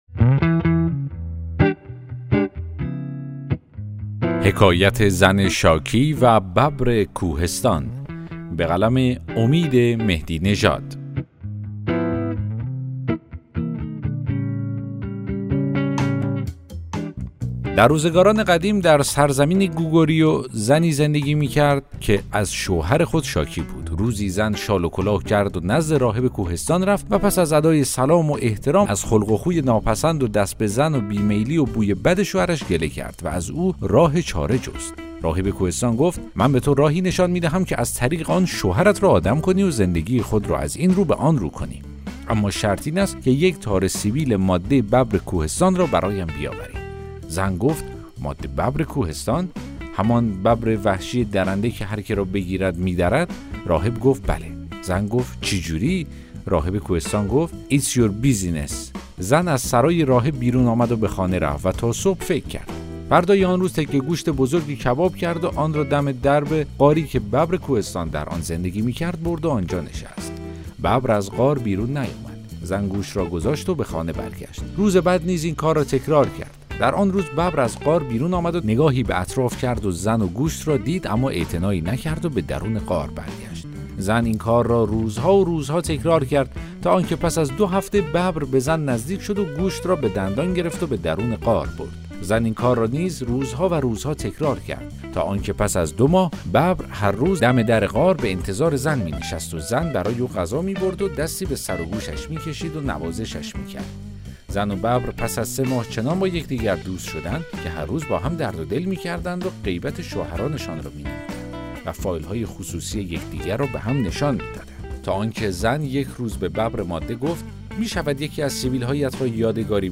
داستان صوتی: حکایت زن شاکی و ببر کوهستان